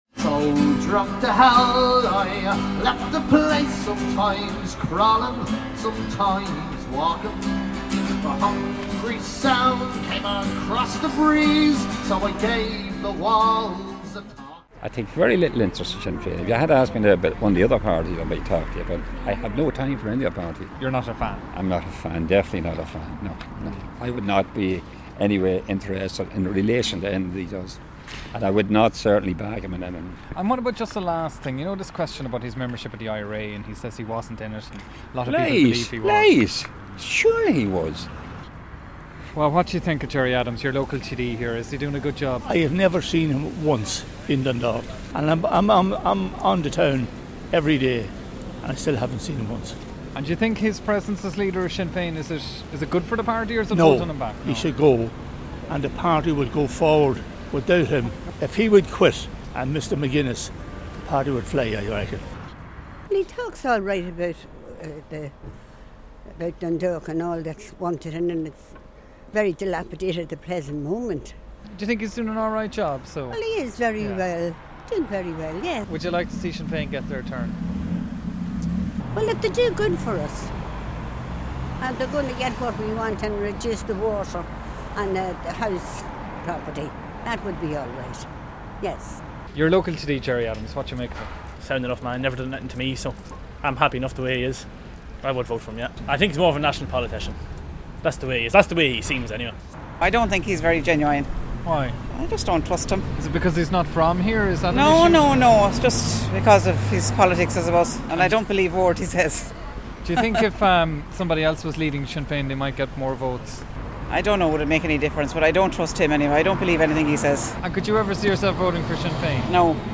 Yesterday, I took a stroll around Dundalk, a few hundred yards from local TD and Sinn Fein leader Gerry Adam's constituency office and asked locals if he was delivering for Louth.